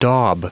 Prononciation du mot daub en anglais (fichier audio)
Prononciation du mot : daub